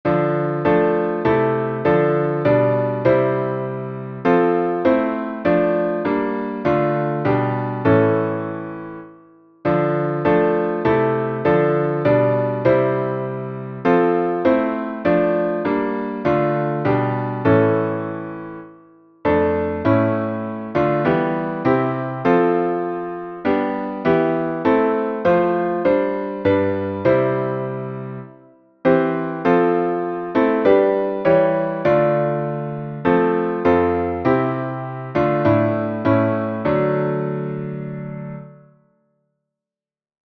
Downloads Áudio Áudio instrumental (MP3) Áudio instrumental (MIDI) Partitura Partitura 4 vozes (PDF) Cifra Cifra (PDF) Cifra editável (Chord Pro) Mais opções Página de downloads
salmo_86B_instrumental.mp3